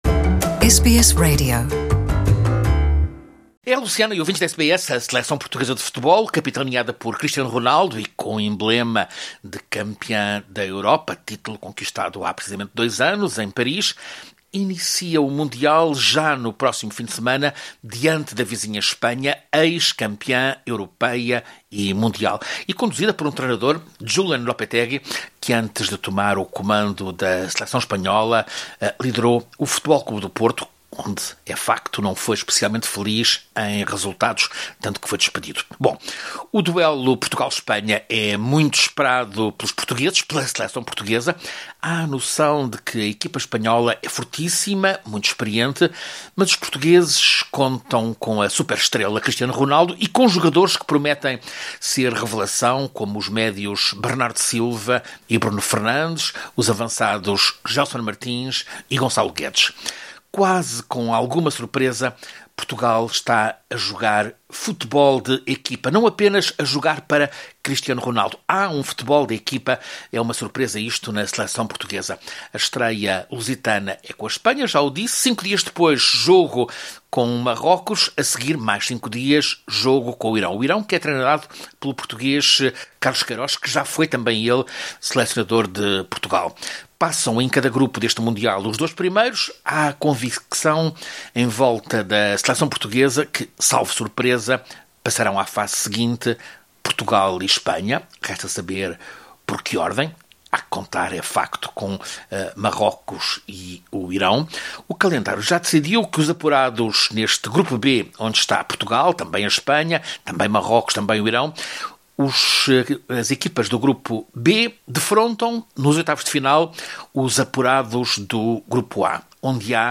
boletim esportivo